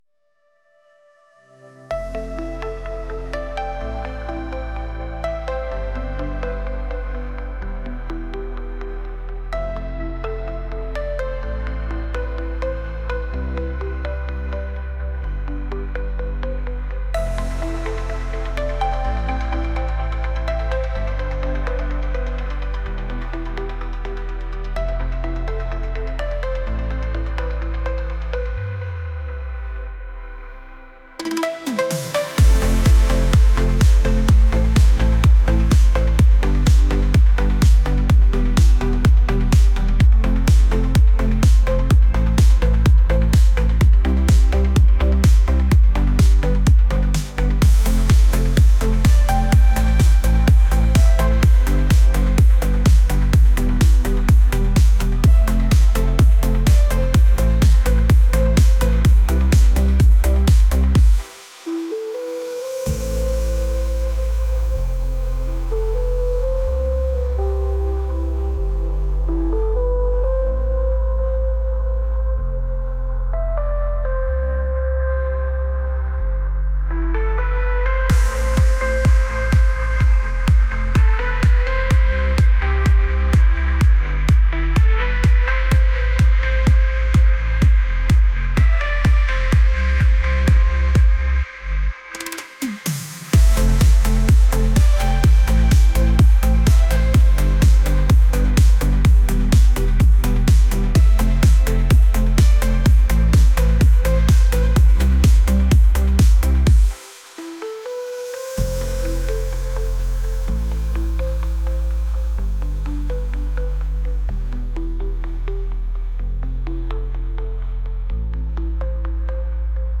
electronic | dreamy | pop